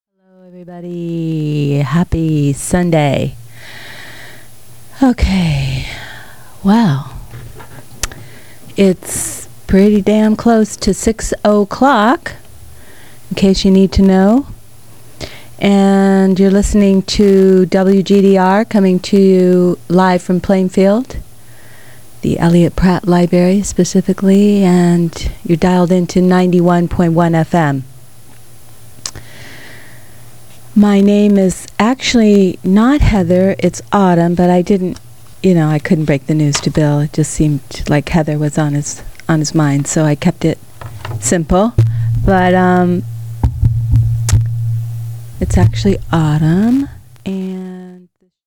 WGDR Top of the Hour Audio: